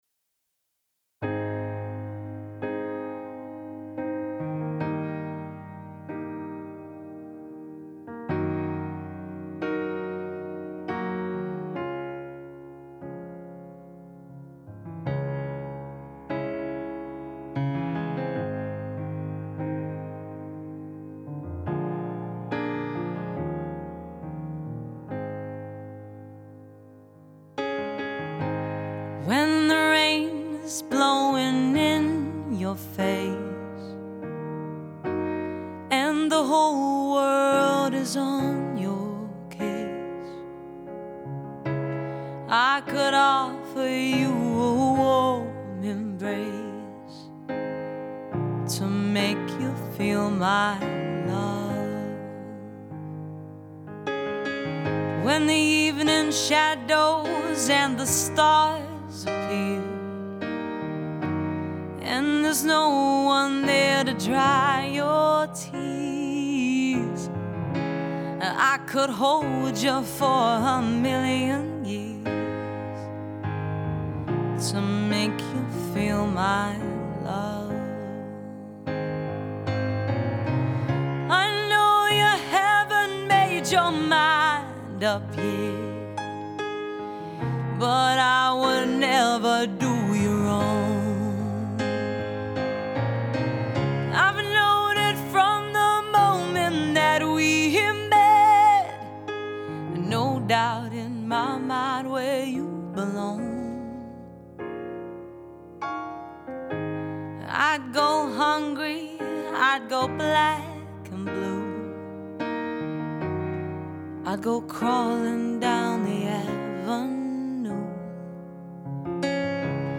Pop, Rock, Soul,  Funk and Jazz .
Live Show Reel